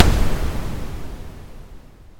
Lightning.mp3